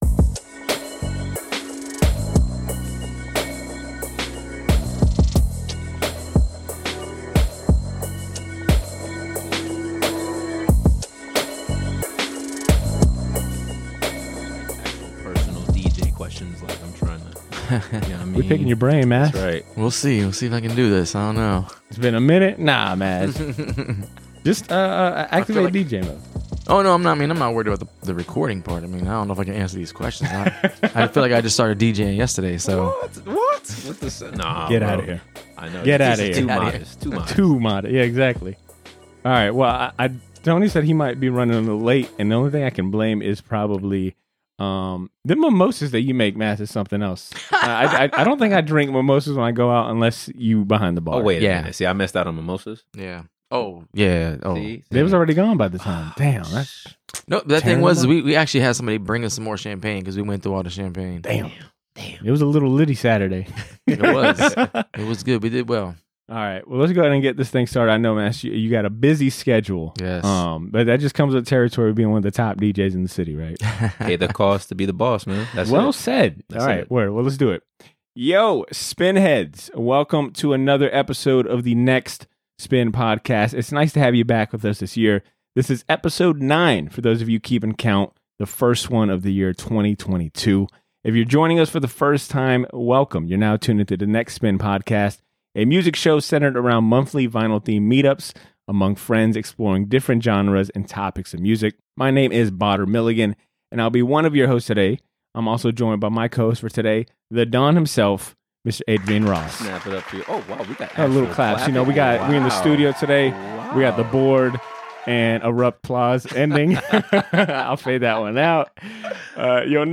A Conversation w